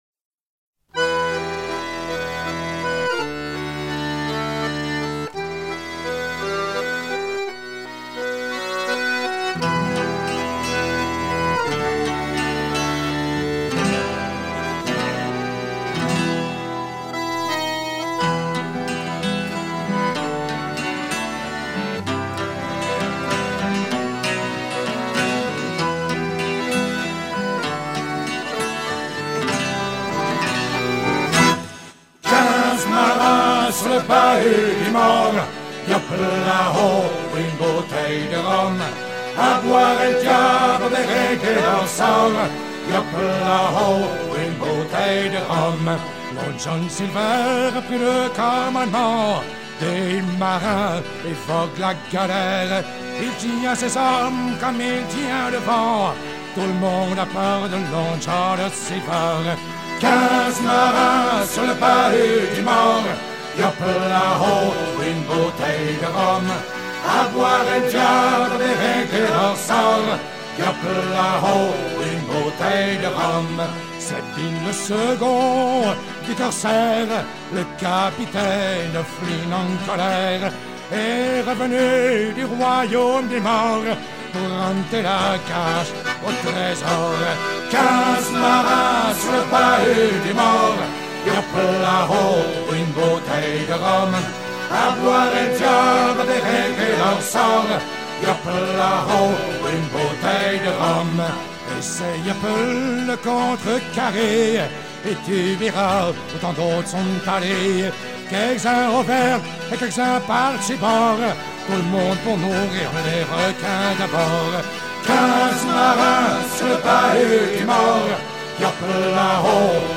Chansons de port
Pièce musicale éditée